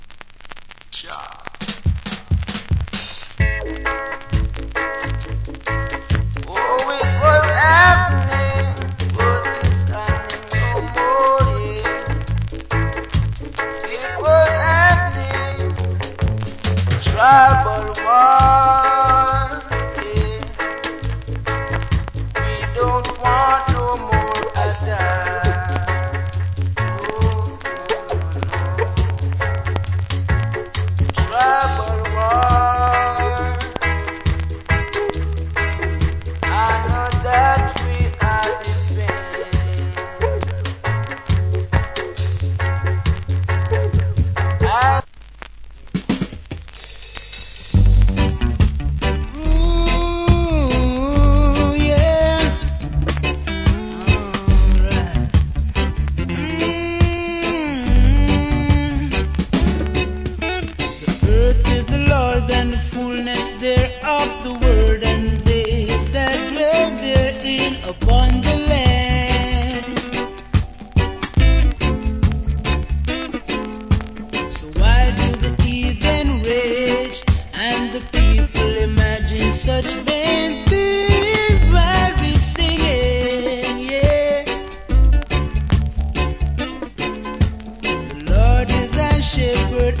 Foundation Roots Rock Vocal.